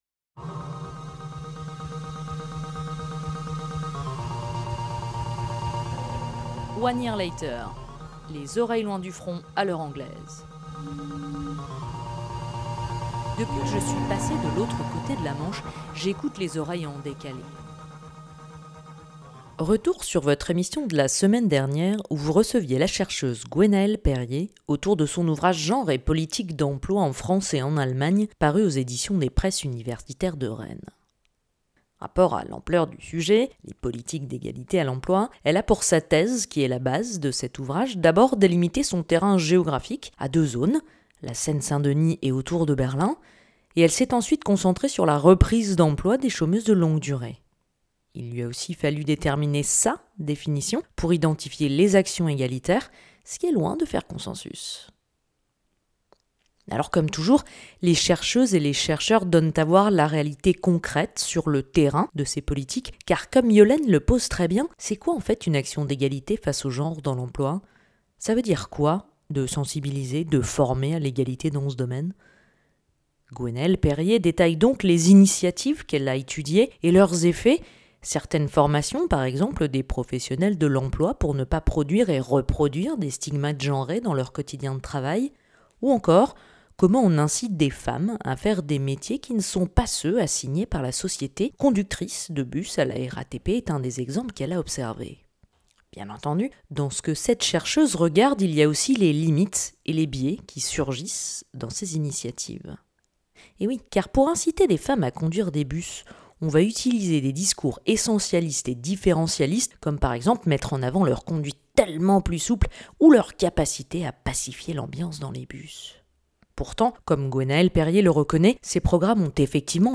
Emission radiophonique en direct tous les mercredis de 19h à 20H30